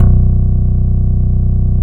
CLEAN BASS-R.wav